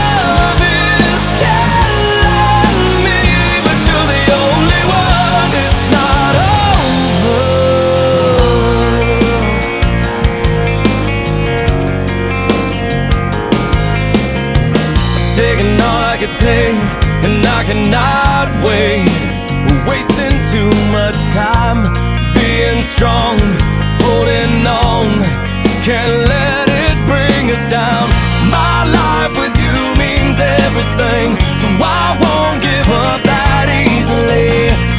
Жанр: Поп / Рок / Альтернатива / Пост-хардкор / Хард-рок